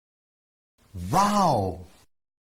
WOW MLG Sound Effect - Download Free MP3 Mp3
WOW-Sound-Effect.mp3